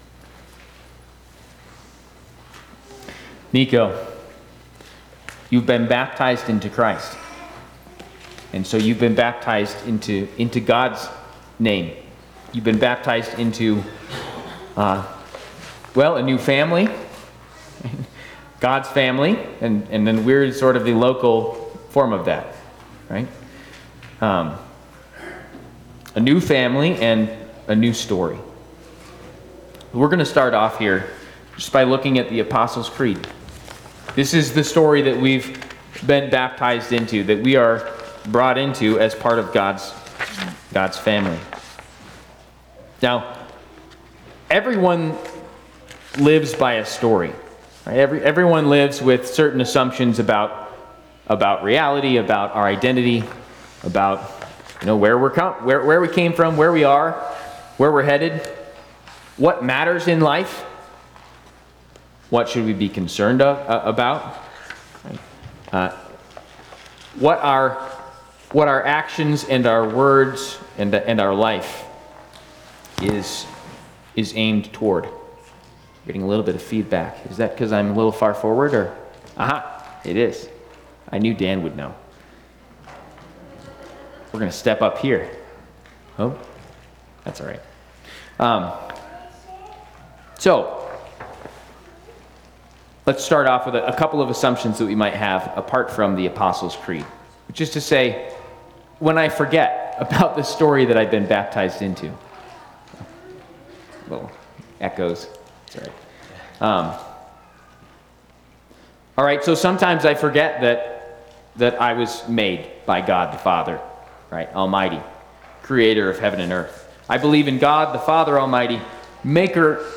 Trinity Lutheran Church, Greeley, Colorado The Story of Your Baptism Sep 21 2025 | 00:24:38 1x 00:00 / 00:24:38 Subscribe Share RSS Feed Share Link Embed